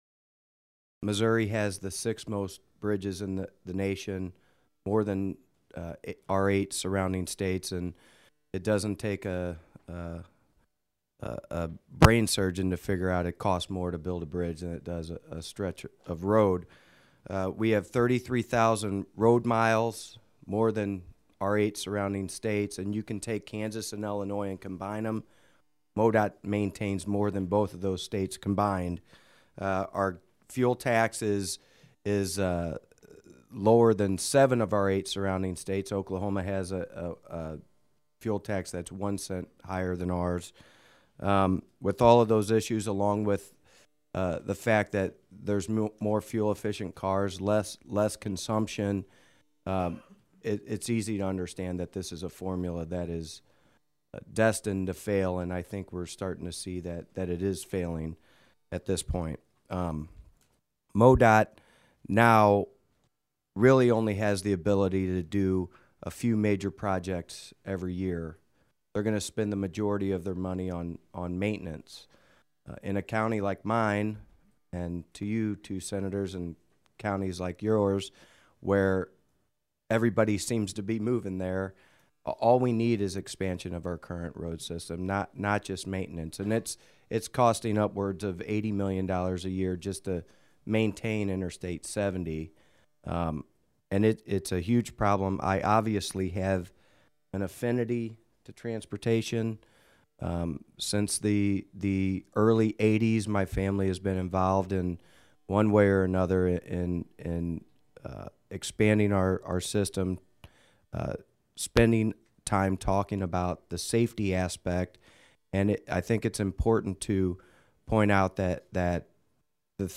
Podcasting | How Topic The following is taken from Sen. McKenna's presentation of SJR 16 to the Senate Transportation and Infrastructure Committee hearing on Feb. 20, 2013.